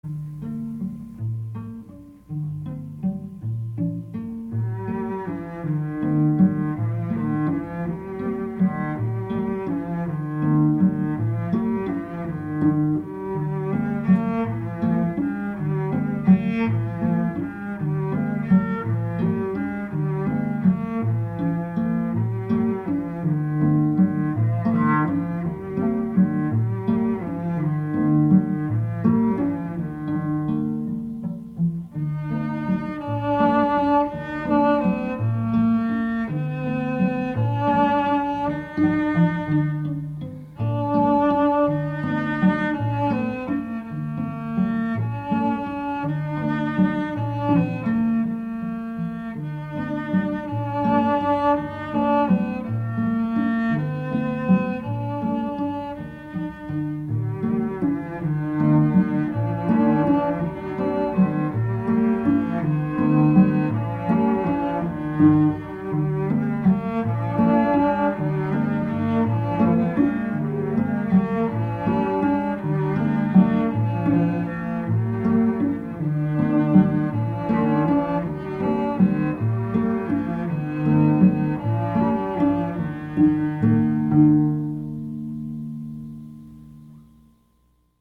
Pièces pour 4 violoncelles